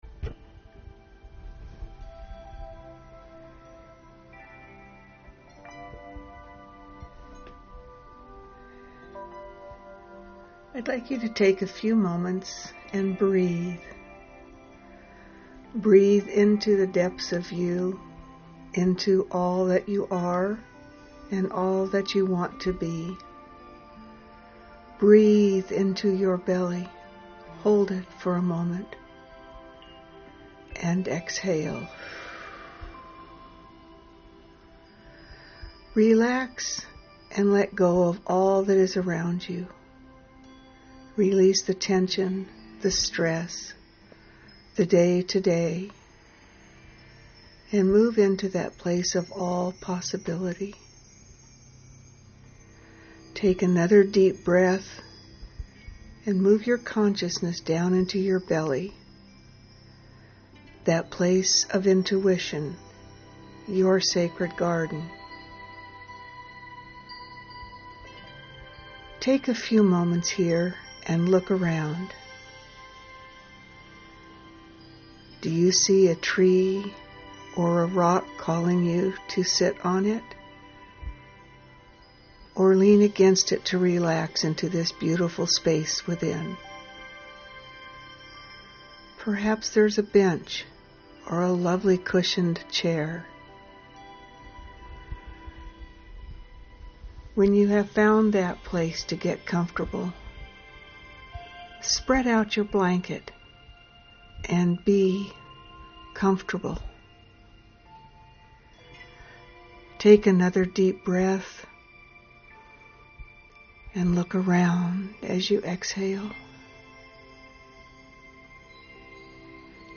meditation-1-to-see-the-path-ahead.mp3